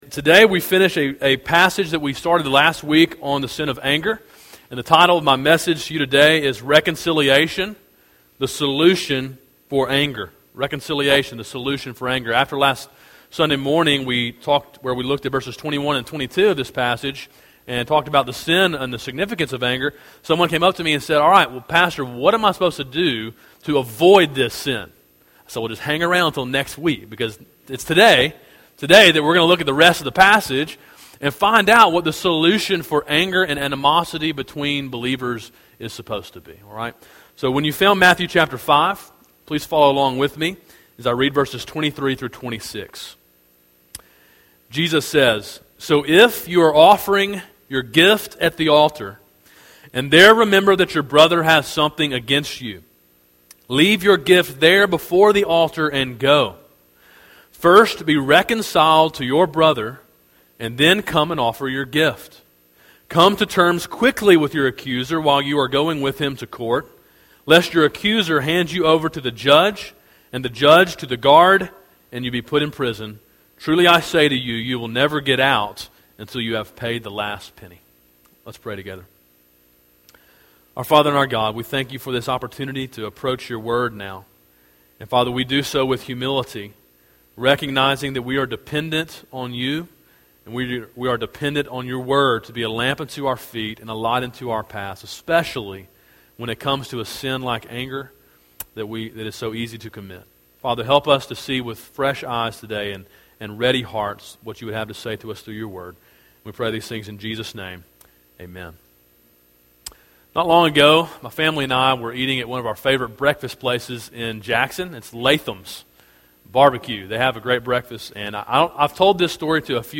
A sermon in a series titled Sermon on the Mount: Gospel Obedience.